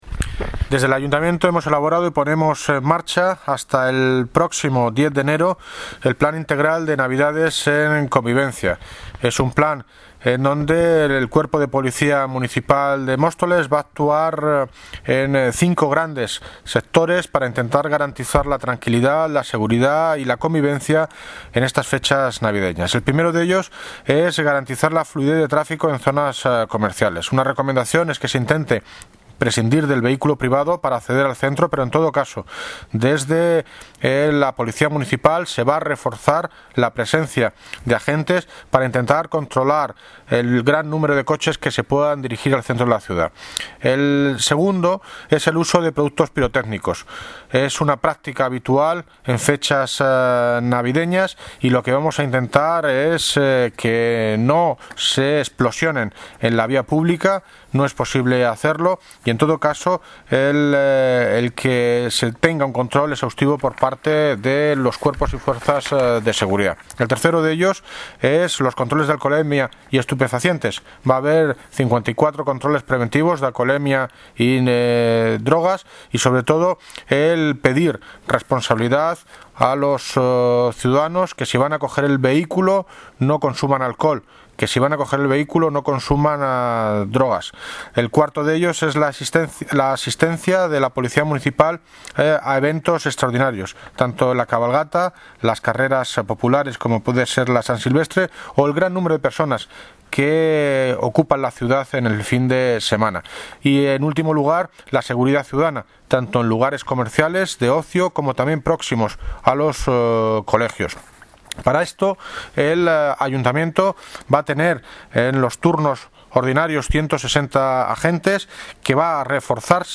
Audio - David Lucas (Alcalde de Móstoles) Sobre el Plan de Navidades en Convivencia
Audio - David Lucas (Alcalde de Móstoles) Sobre el Plan de Navidades en Convivencia.mp3